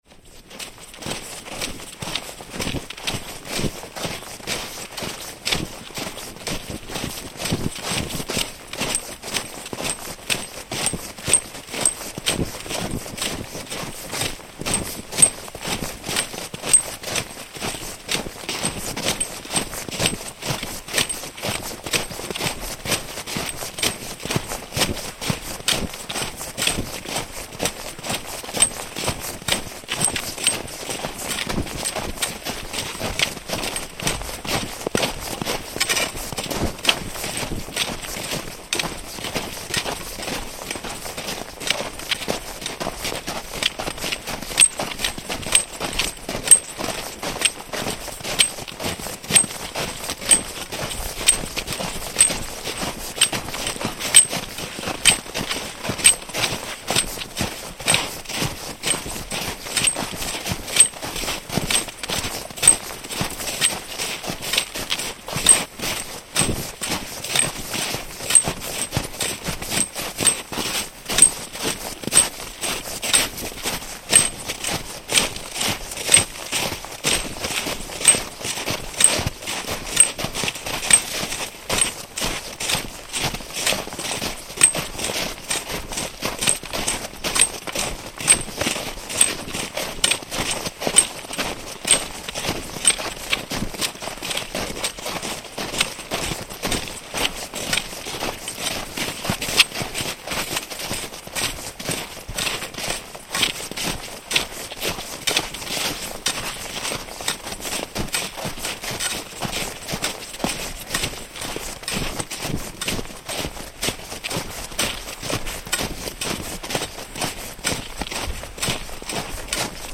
The sound of walking down Monte Rite in the Italian Dolomites with the help of ciaspole (snowshoes) and ski poles.